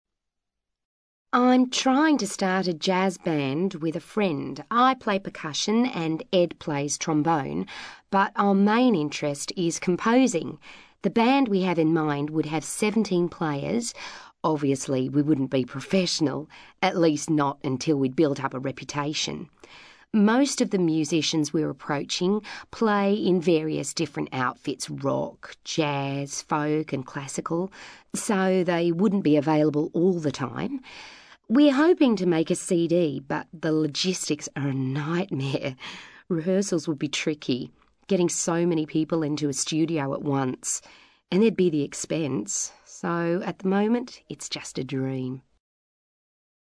ACTIVITY 183: You will hear five short extracts in which five people are talking about an aspect of music which is or has been important to them.